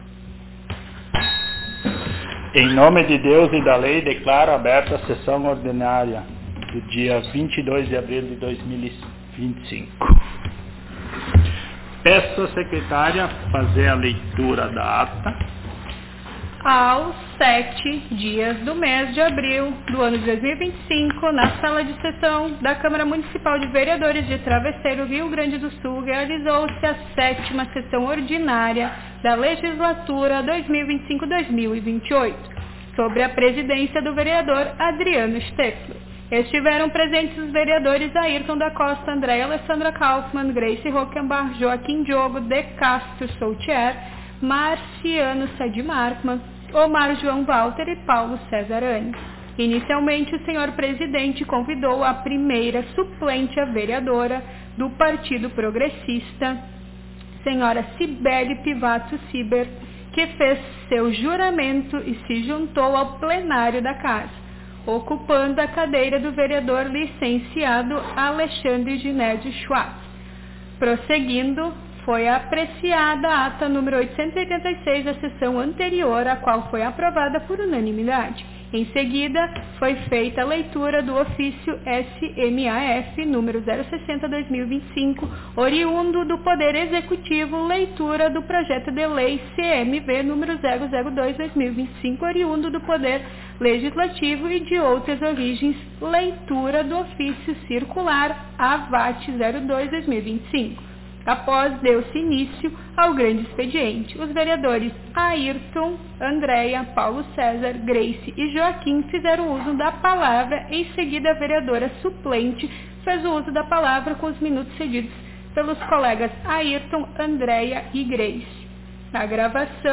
Aos 22 (vinte e dois) dias do mês de abril do ano de 2025 (dois mil e vinte e cinco), na Sala de Sessões da Câmara Municipal de Vereadores de Travesseiro/RS, ocorreu a Oitava Sessão Ordinária da Legislatura 2025-2028.